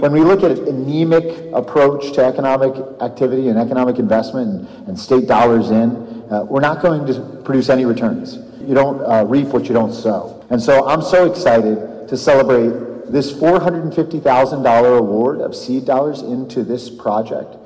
The full-service grocery will bring foods and household staples to a documented food desert in the city.  DHCD Secretary Jake Day was on hand at the construction launch and said community investments like the Horizon Market help the economic engine get started…